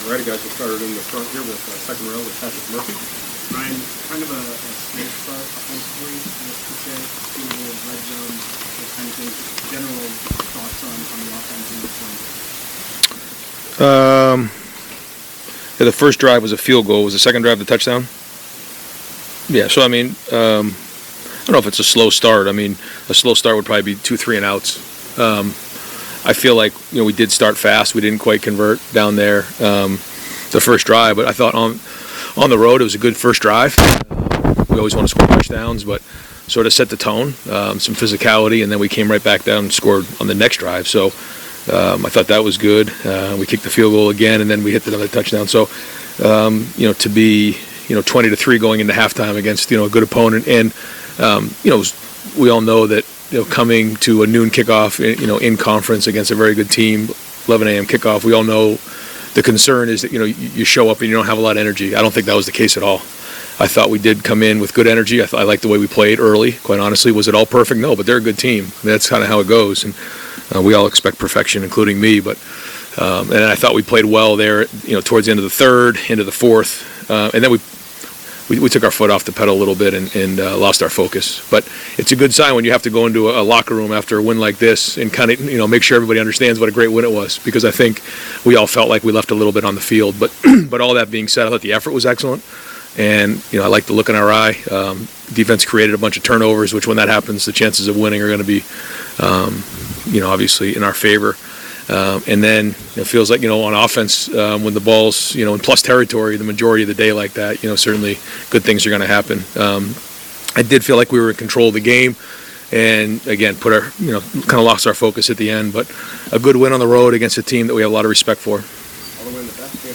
Ryan Day Ohio State head coach talks to media after win at Illinois on Saturday (courtesy Ohio State Athletics)